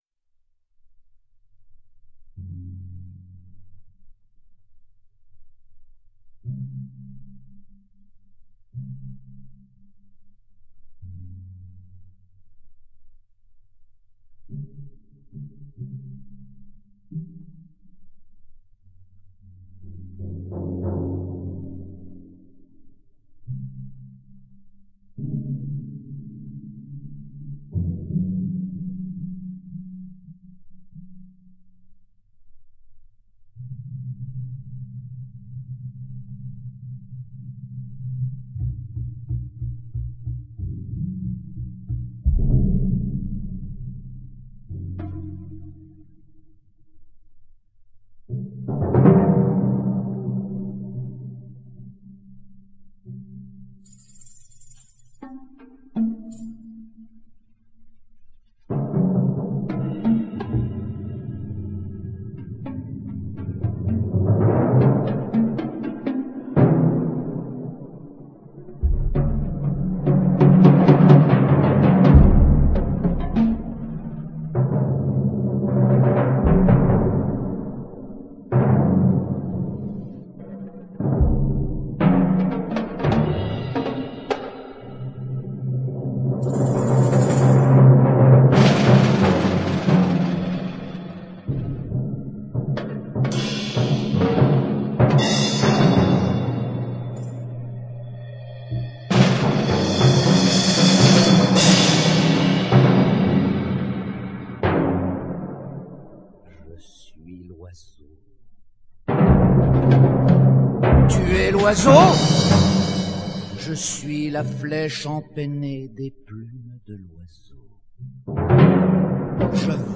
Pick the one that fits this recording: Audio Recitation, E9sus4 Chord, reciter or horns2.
reciter